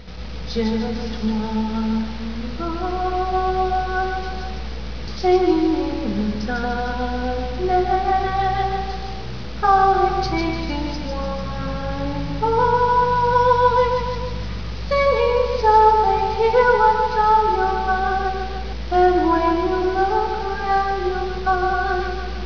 I decided to put my voice where my mouth is